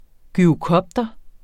Udtale [ gyʁoˈkʌbdʌ ]